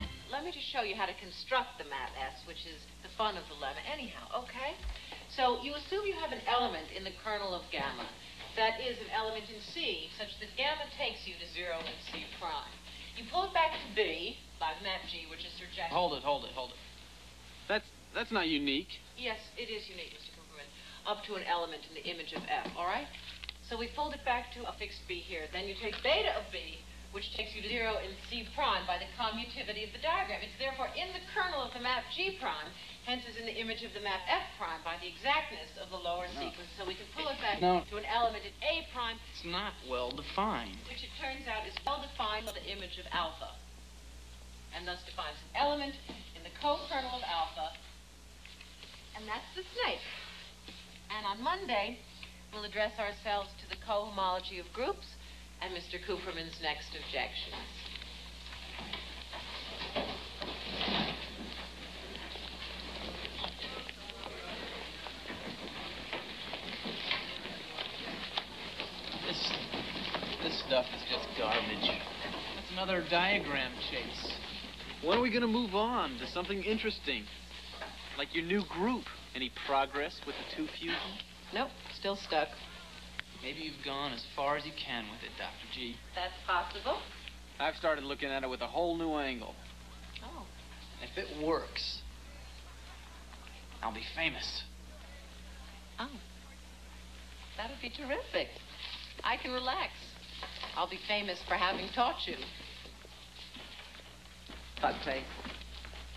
On this page, I have only 5 sequence examples taken from actual movies.
The end of a lecture in algebraic topology. The Snake lemma in homological algebra is presented and a short discussion with a student follows.